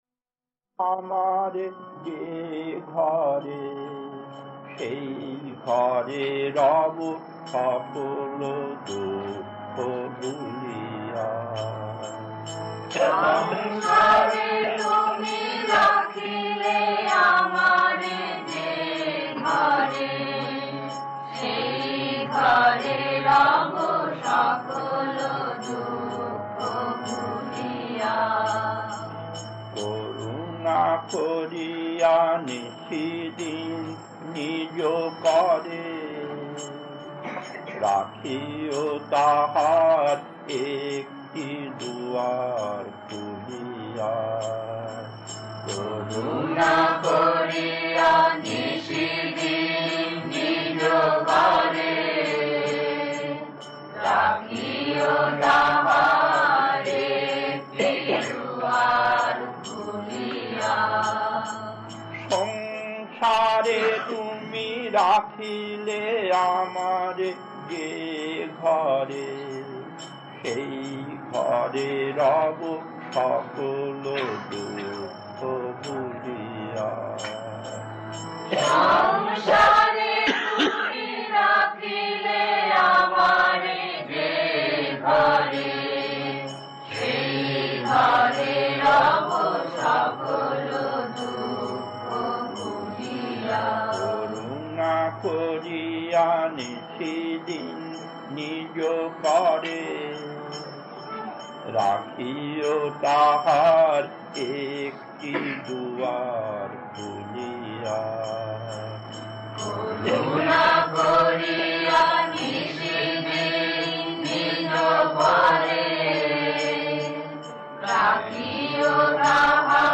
Kirtan B6-1 Chennai 1980, 46 minutes 1.